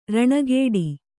♪ raṇagēḍi